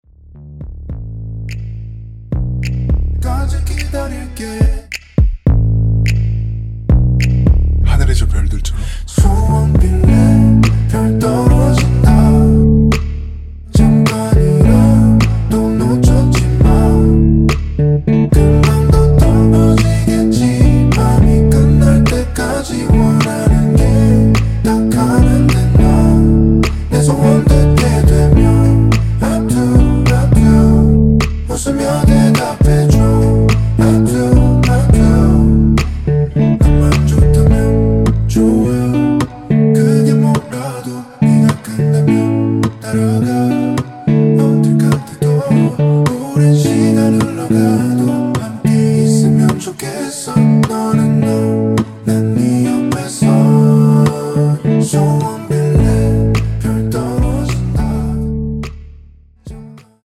원키에서(-2)내린 코러스 포함된 MR입니다.(미리듣기 확인)
앞부분30초, 뒷부분30초씩 편집해서 올려 드리고 있습니다.